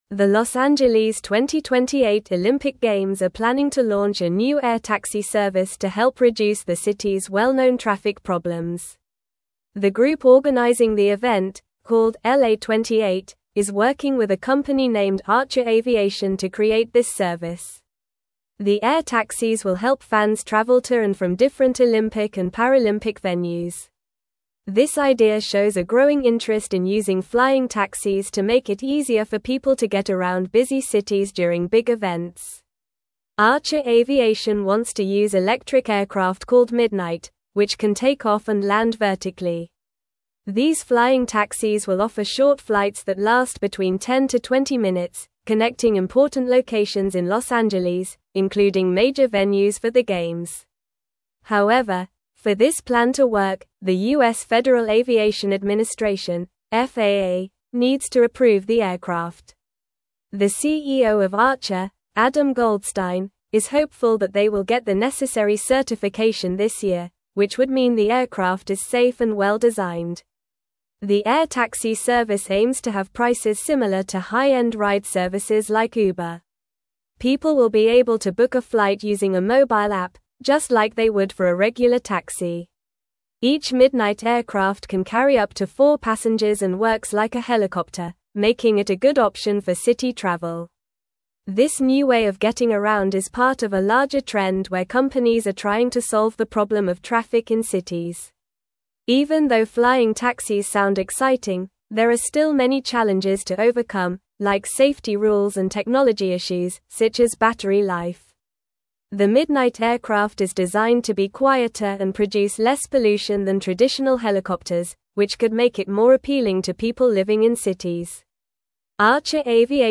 Normal